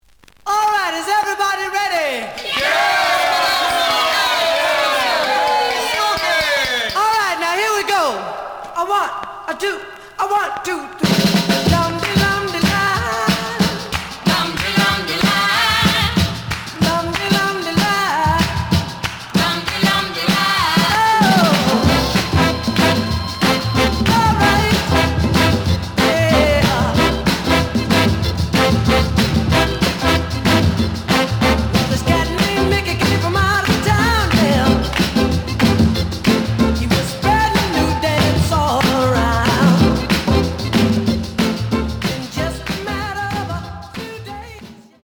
The audio sample is recorded from the actual item.
●Genre: Soul, 60's Soul